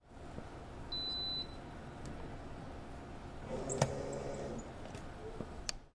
咖啡机
描述：咖啡机从豆荚里煮一杯咖啡。
标签： 咖啡机 酿造 咖啡
声道立体声